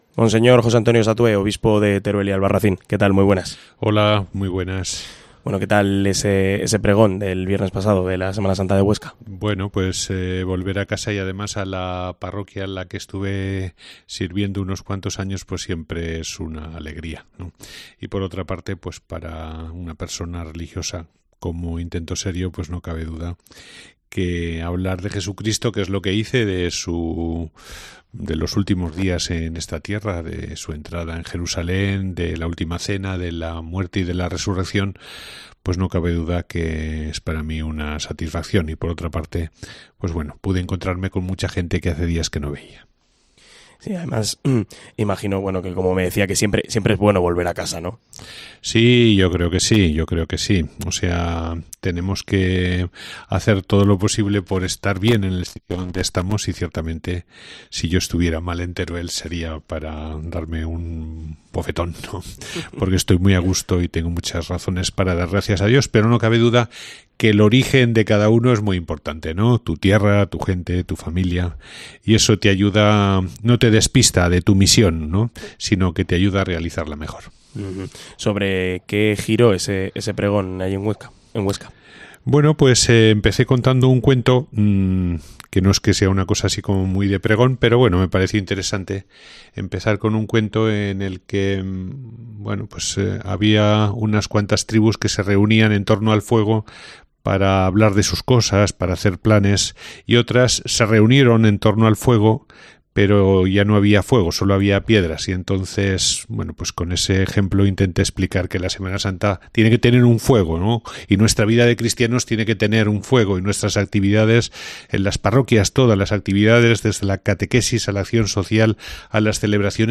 El obispo de Teruel y Albarracín, don José Antonio Satué, ha pasado por los micrófonos de COPE para hablar de la Semana Santa
Entrevista a don José Antonio Satué, obispo de Teruel y Albarracín